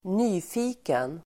Uttal: [²n'y:fi:ken]